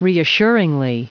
Prononciation du mot reassuringly en anglais (fichier audio)
reassuringly.wav